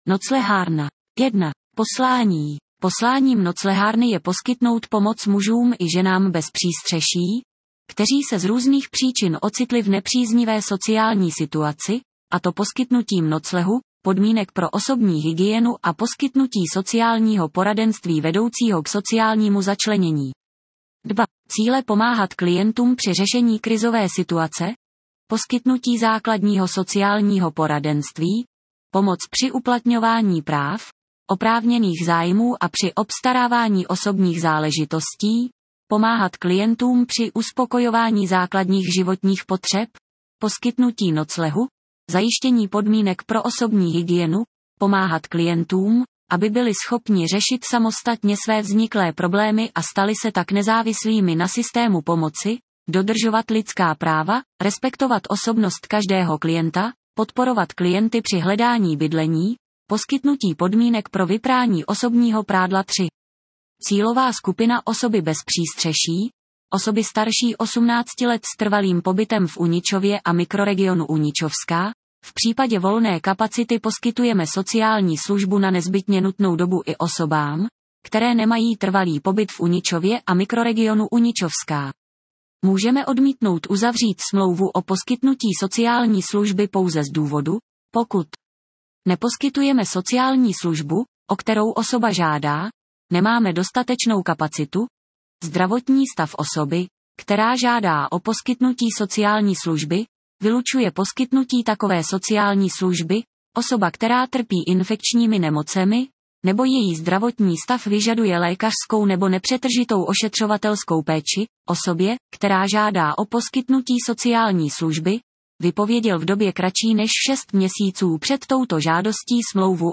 PŘEČÍST NAHLAS: